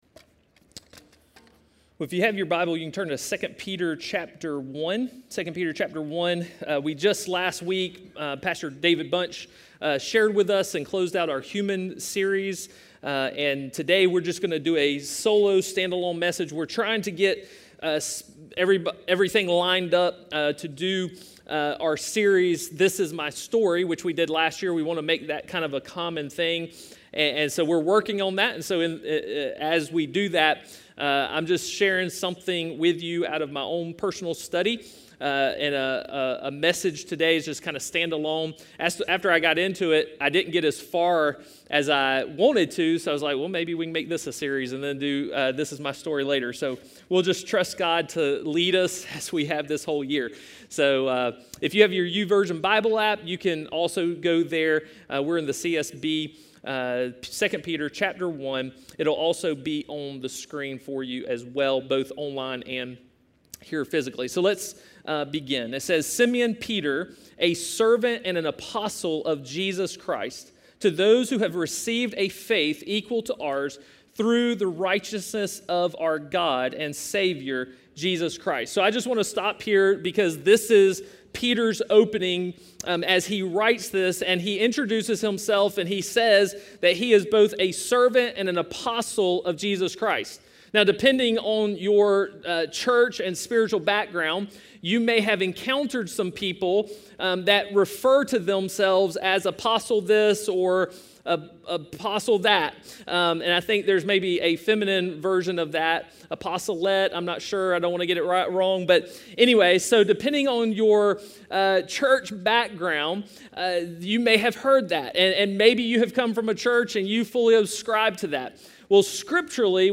Solo sermons are stand alone sermons that do not belong to a particular series, or they are preached by a guest preacher.